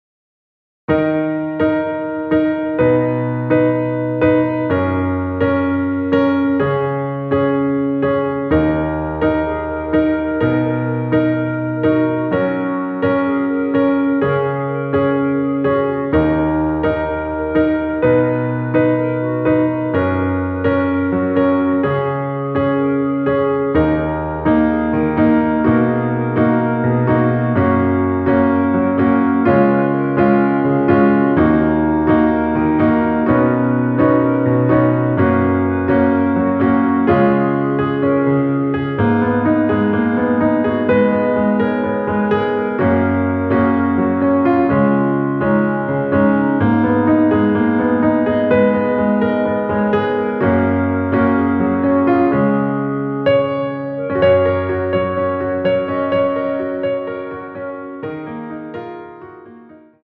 원키에서(-2)내린 멜로디 포함된 MR입니다.(미리듣기 확인)
Gb
앞부분30초, 뒷부분30초씩 편집해서 올려 드리고 있습니다.
중간에 음이 끈어지고 다시 나오는 이유는